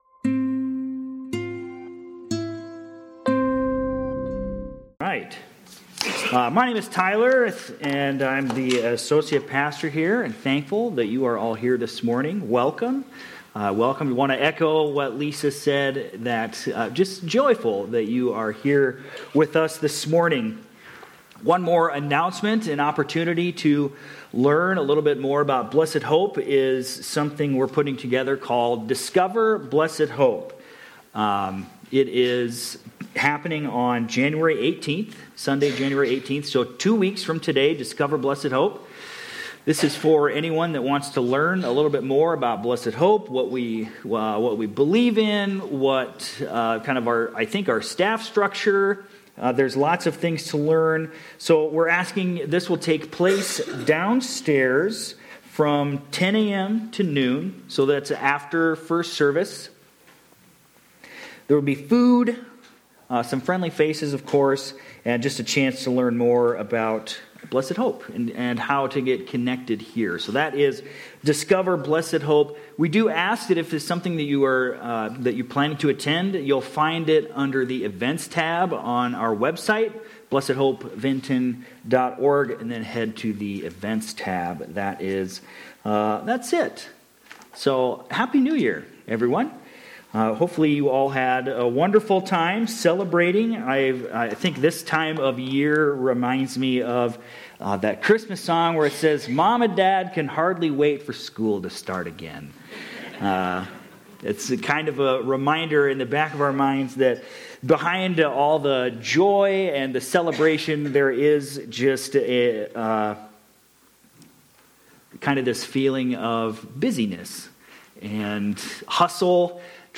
Jan-4-26-Sermon-Audio.mp3